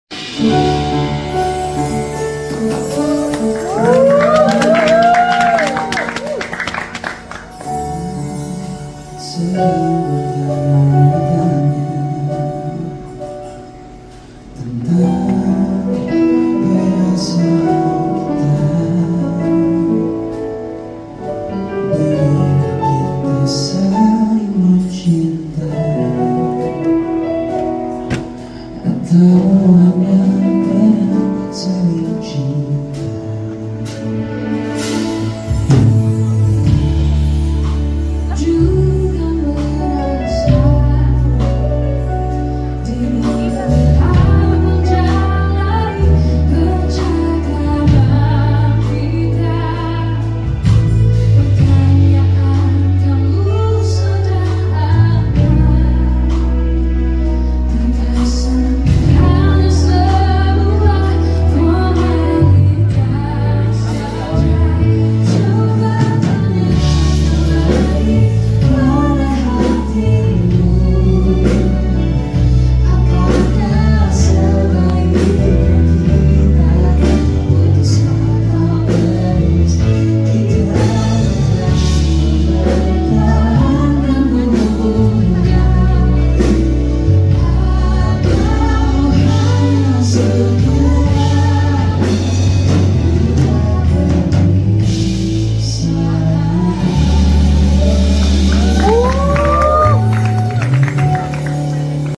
kita dengerin suara emasnya